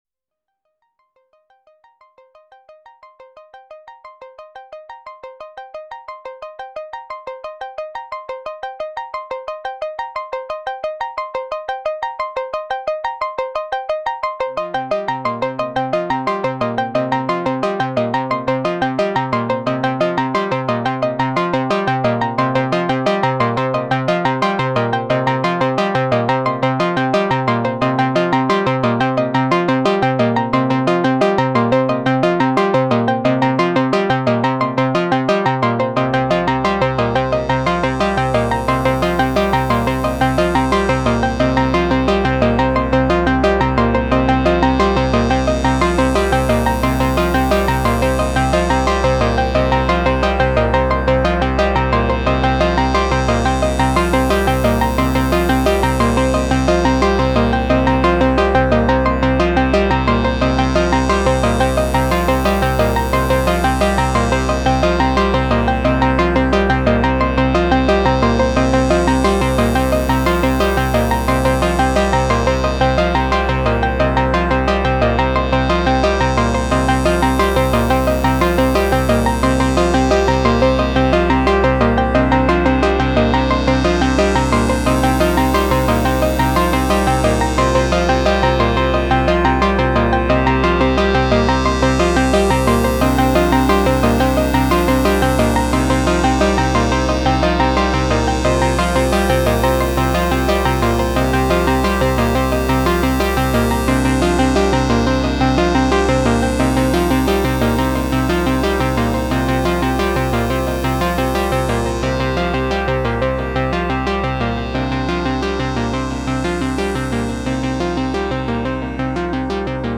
Berlin school messing (Moog Grandmother/Mother-32/Subharmonicon).